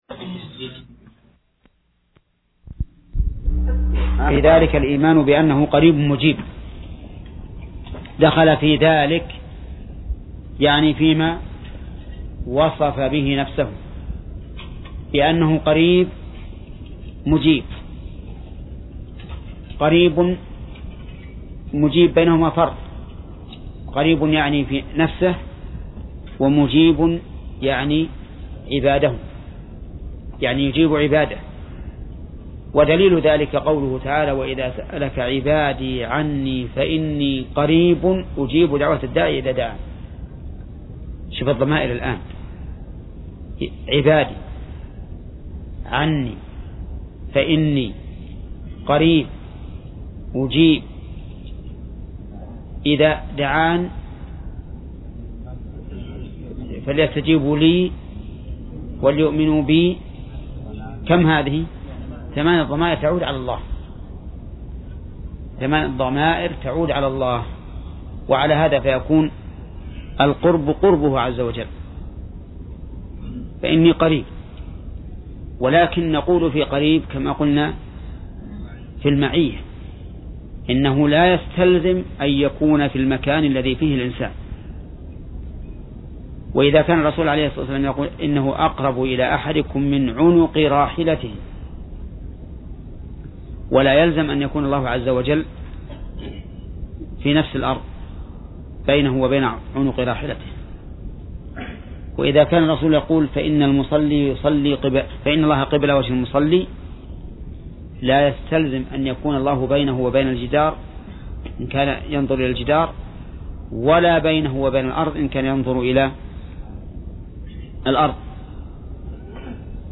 درس (27) : من صفحة: (89)، (قوله: فصل في قرب الله تعالى ....)، إلى صفحة: (109)، (قوله: فأما الفتنة ....).